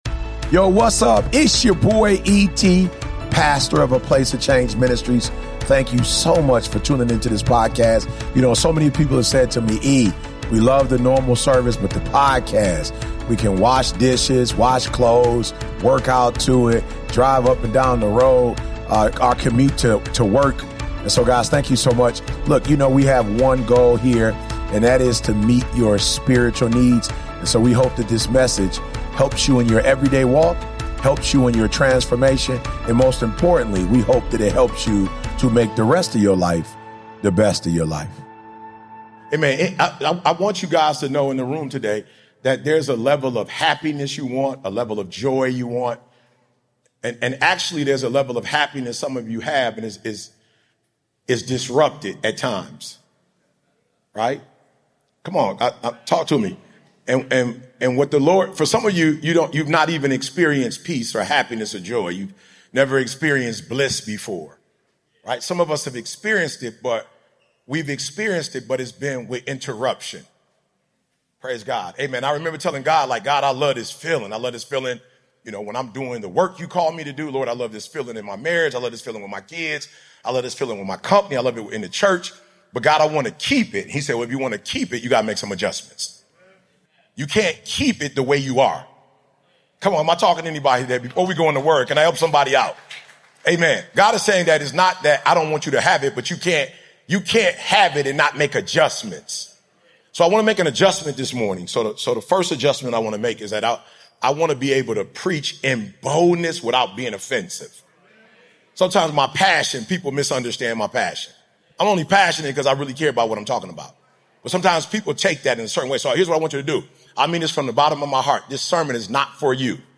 Includes live prayer and declaration for healing and spiritual alignment